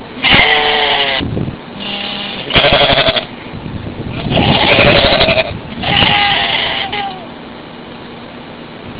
As you can hear - the sheep make a terric noise.
sheep.wav